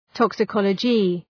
Προφορά
{,tɒksə’kɒlədʒı}
toxicology.mp3